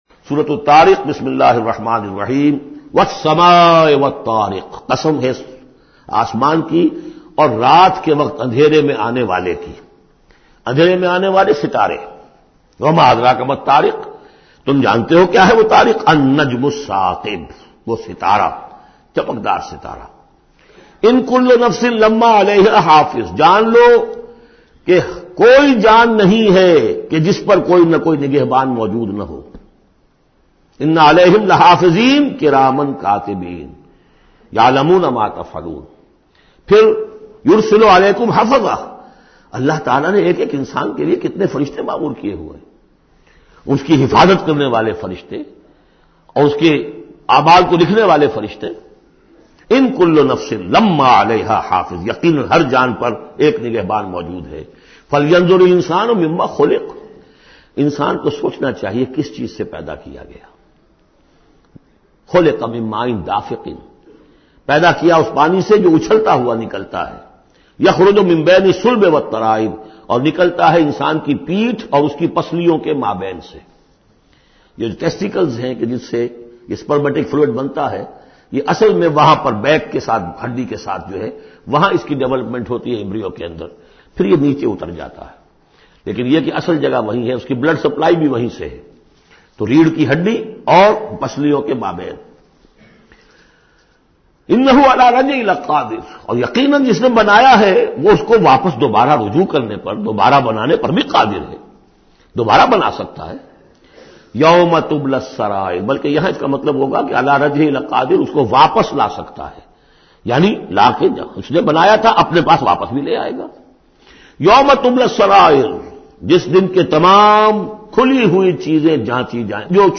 Surah Tariq is 86 chapter of Holy Quran. Listen online mp3 audio tafseer of Surah Tariq in the voice of Dr Israr Ahmed.